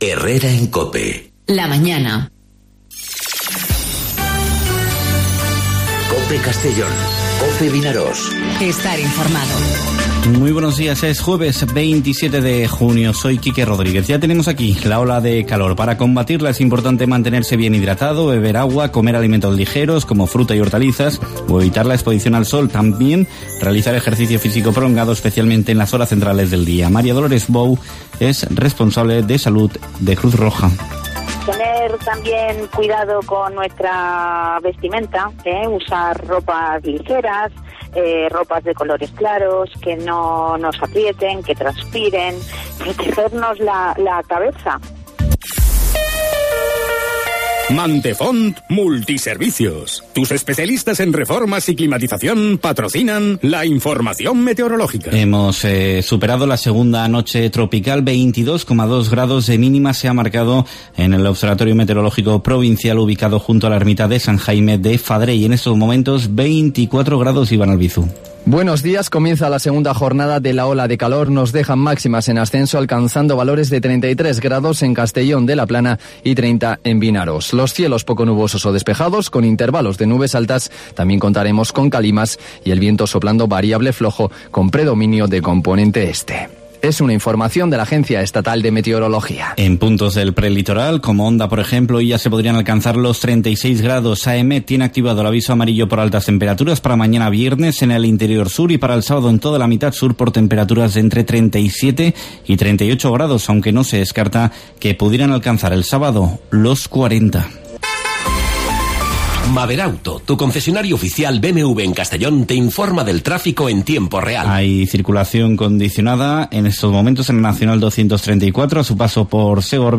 Informativo 'Herrera en COPE' Castellón (27/06/2019)